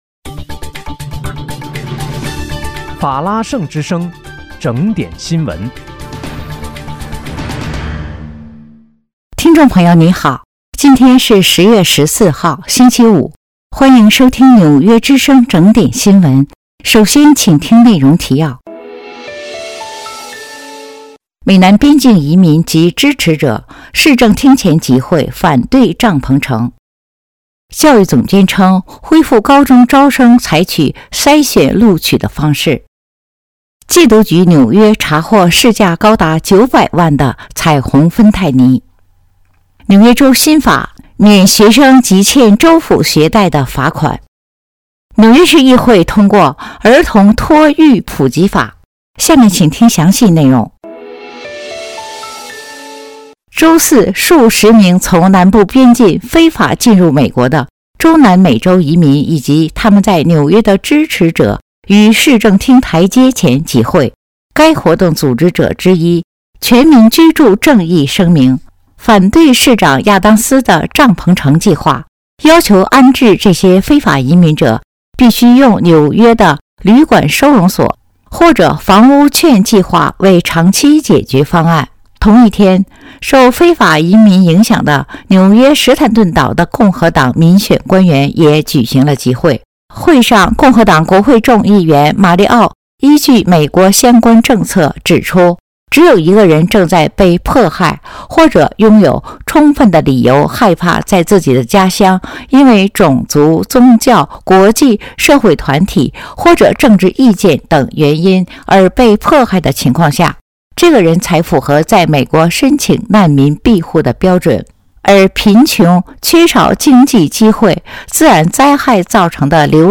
10月14号（星期五）纽约整点新闻。